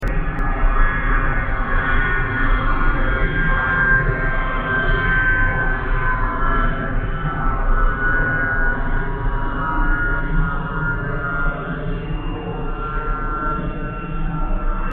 Additionally, exemplary real-time auralizations of aircraft noise based on the presented method for interpolating simulation results are provided (linear